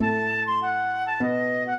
flute-harp
minuet8-12.wav